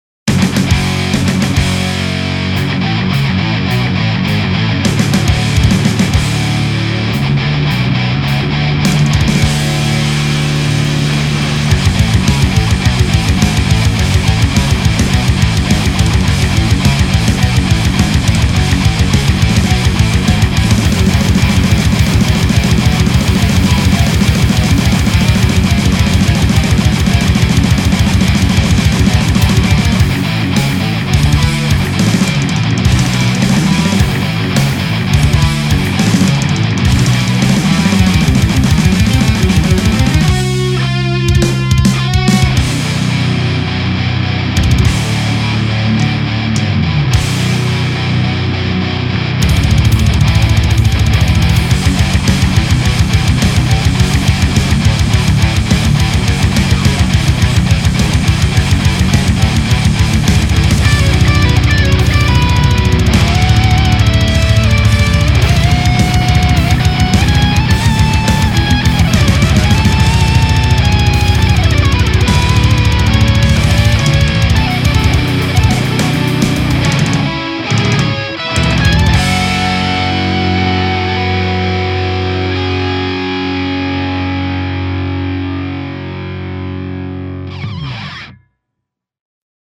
Je voulais vous faire partager ma derniere creation avec mon randall rg 50 utilisé au guitare .
J'adore vraiment le son auquel je suis arrivé, juste avec une MXR m108 en plus dans la boucle. J'ai repris le HP d'origine avec deux sm 57, aucune simulation de HP ou d'ampli de puissance (j'ai testé, et j'aime moin le son justement) :